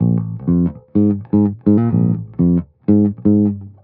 23 Bass Loop B.wav